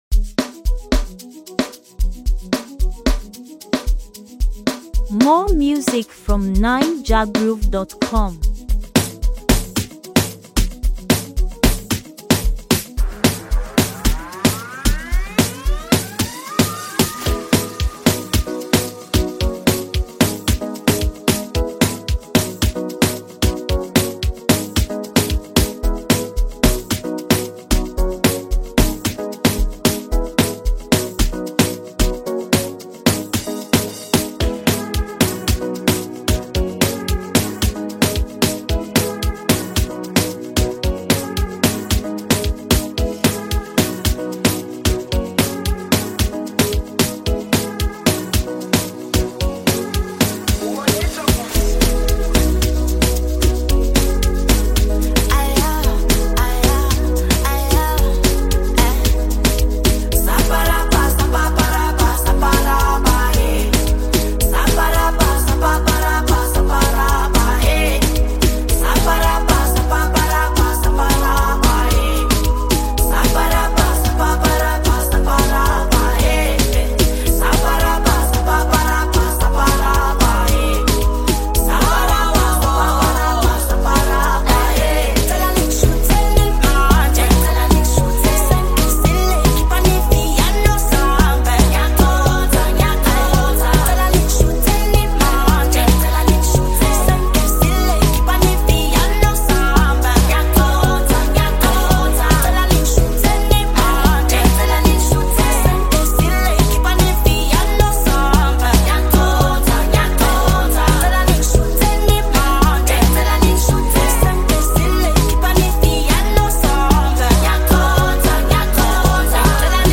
The vibrant South African singer
a mesmerising melody